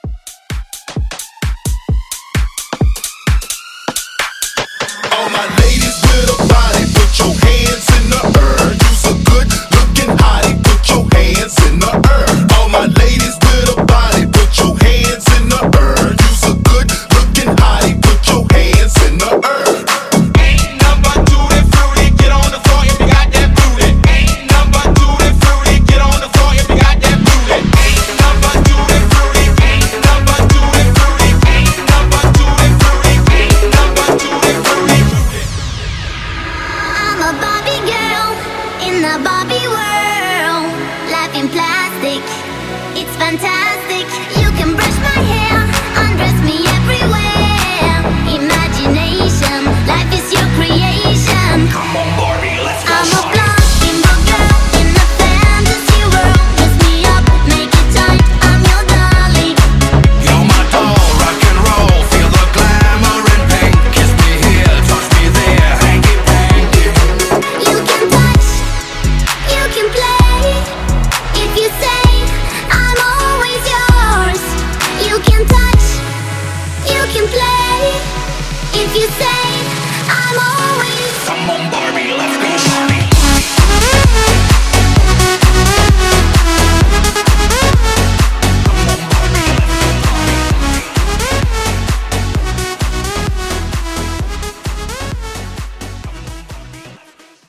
Genre: 90's
Clean BPM: 130 Time